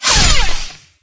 cb_ls_hitsoft1.wav